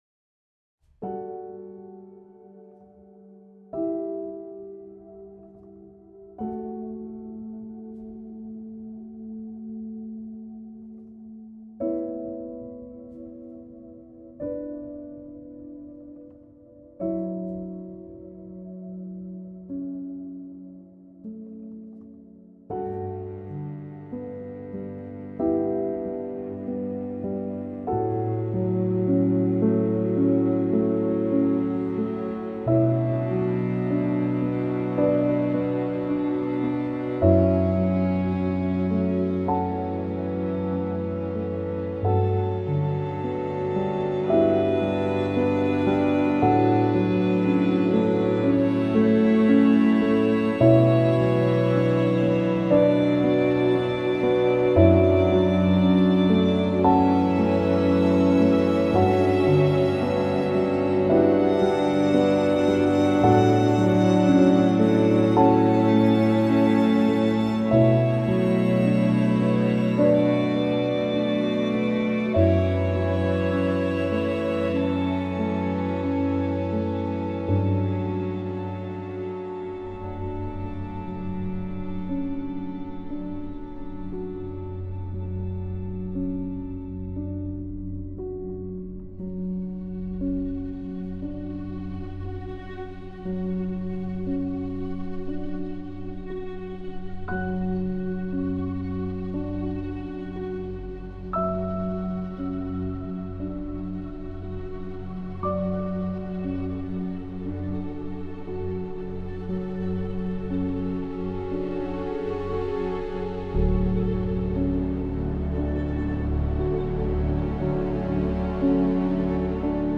Música Original: